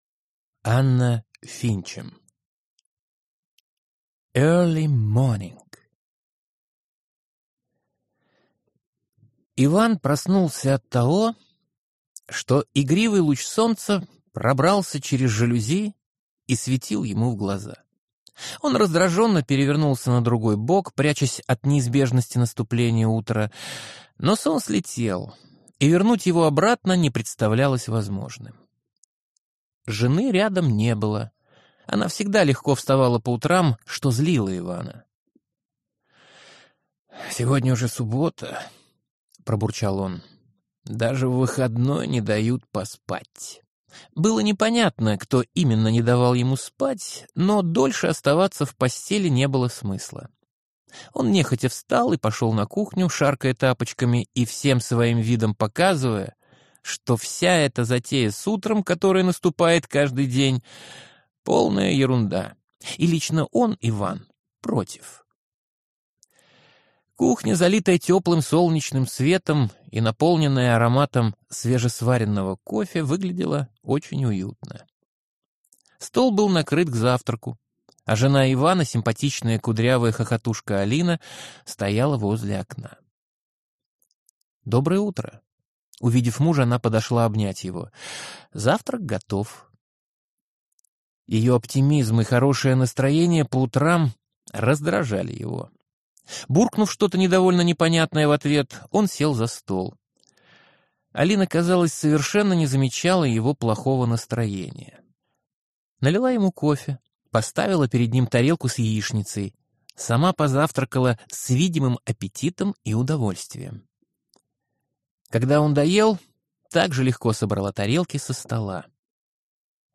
Аудиокнига Early morning | Библиотека аудиокниг
Прослушать и бесплатно скачать фрагмент аудиокниги